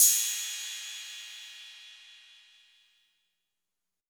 Closed Hats
MB Hi Hat (22).wav